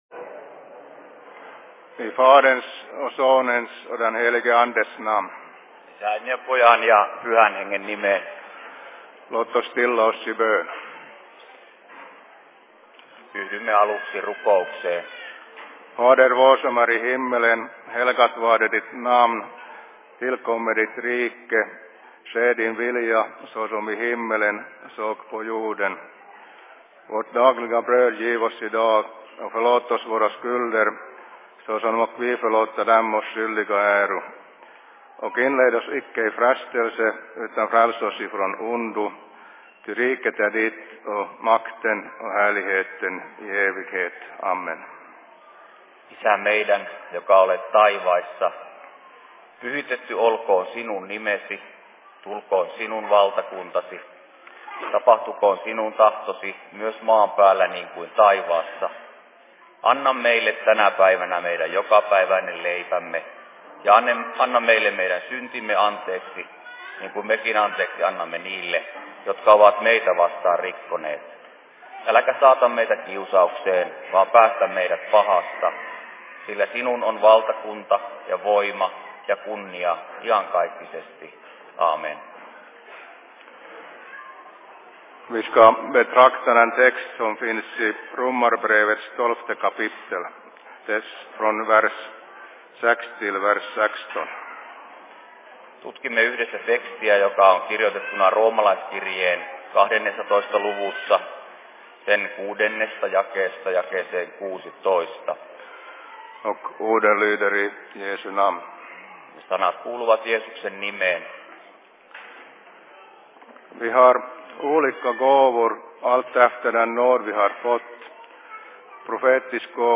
Se Fi Seurapuhe 31.01.2016
Paikka: Rauhanyhdistys Helsinki
Simultaanitulkattu